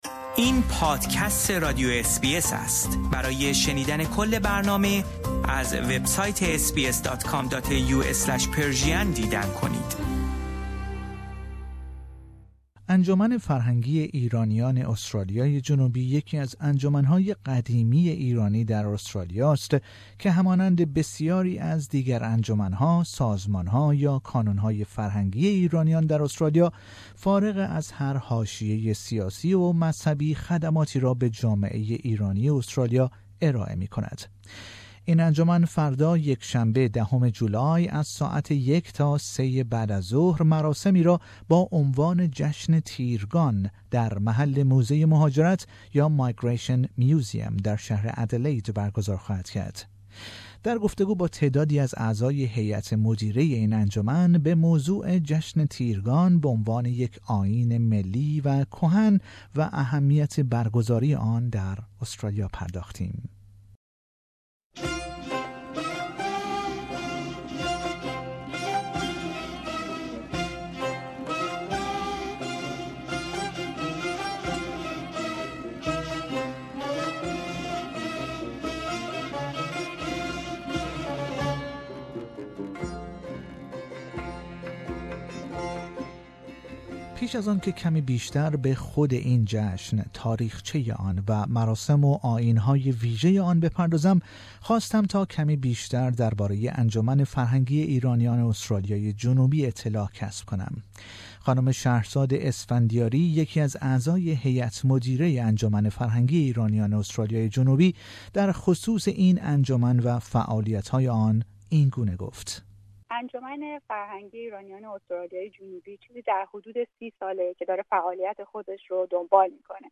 In an interview with a few members of PCASA's steering board we discussed Tirgan, its historical background, related rituals, its importance, and the event that is to be held at Migration Museum in Adelaide, South Australia.